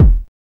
MELLOW RAW.wav